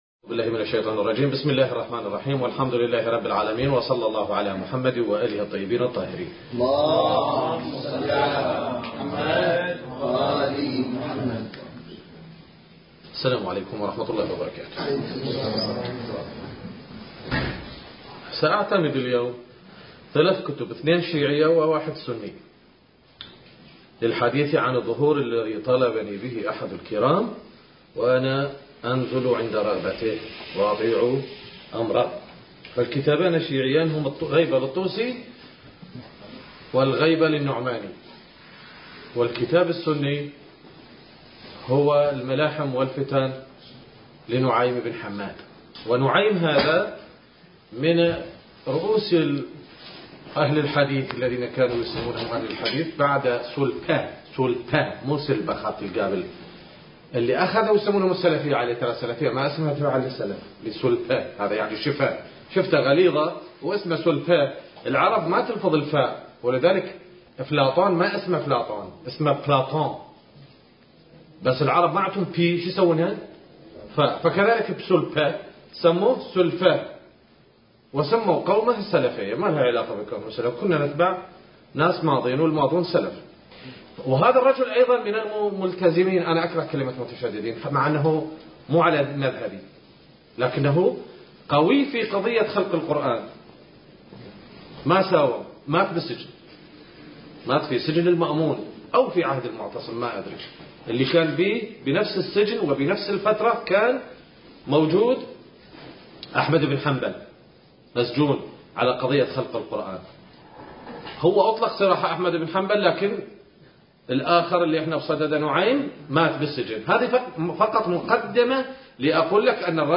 المكان: مضيف الإمام الحسن المجتبى (عليه السلام) / البصرة - سفوان التاريخ: 2022